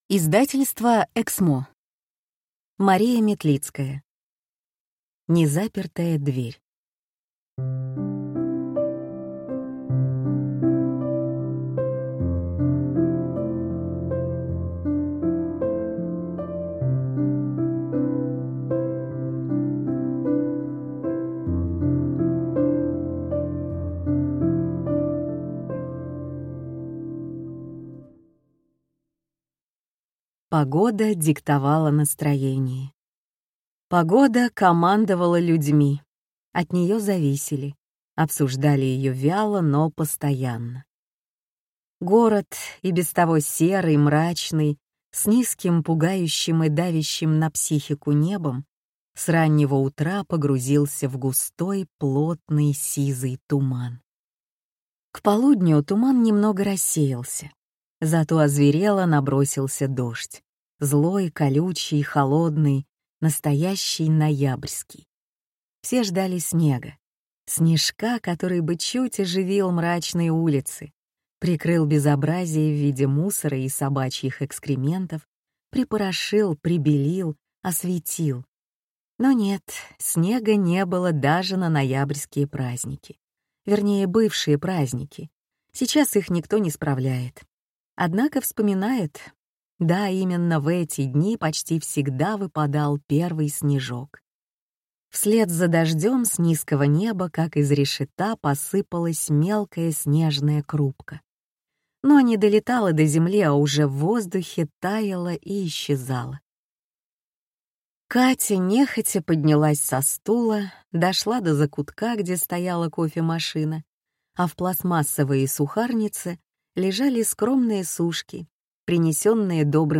Аудиокнига Незапертая дверь | Библиотека аудиокниг